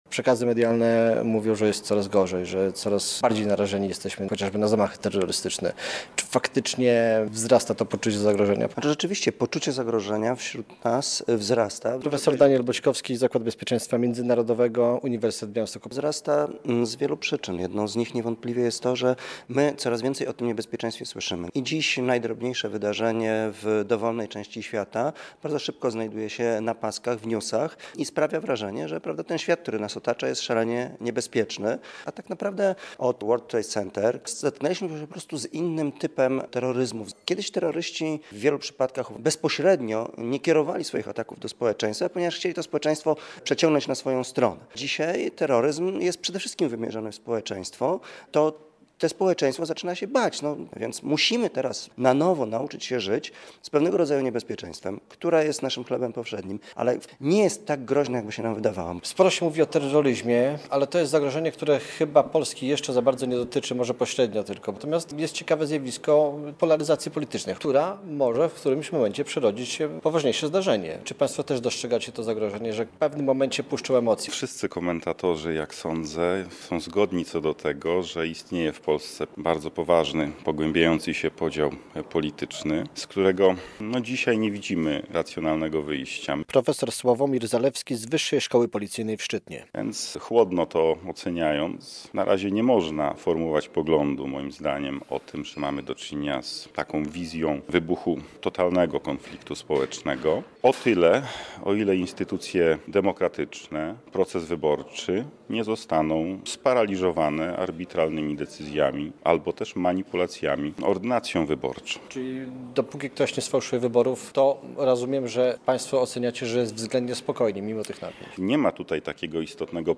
Pospolita przestępczość, cyberataki i narastający radykalizm polityczny - to realne zagrożenia w Polsce - relacja